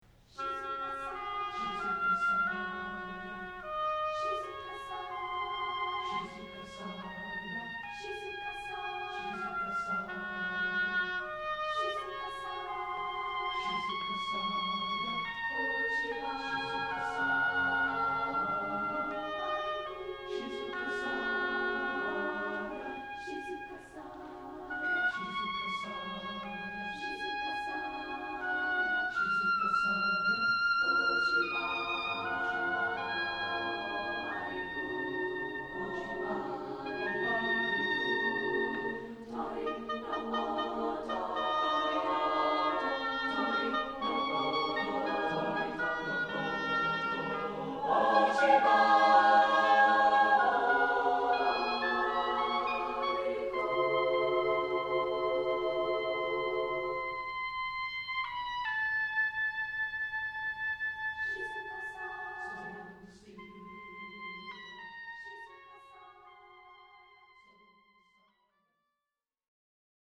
SATB and oboe